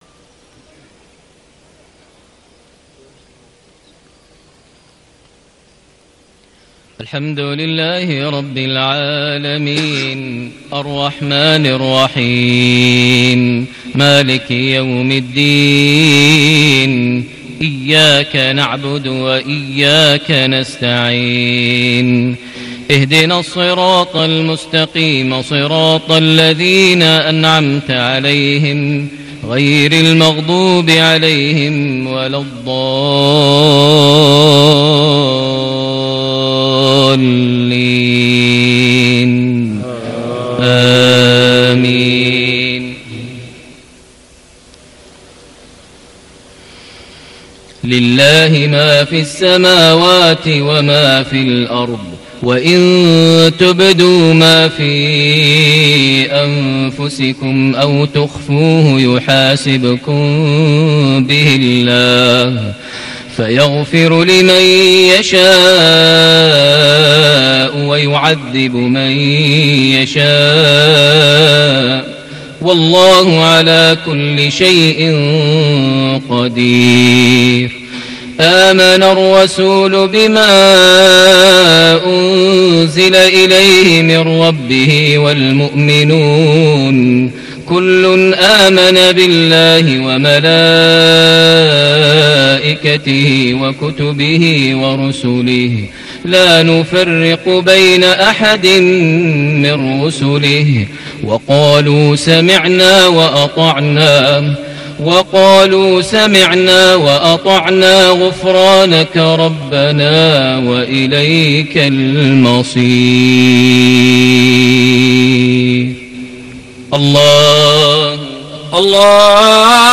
صلاة المغرب ٣٠ شعبان ١٤٣٨هـ خواتيم سورة البقرة > 1438 هـ > الفروض - تلاوات ماهر المعيقلي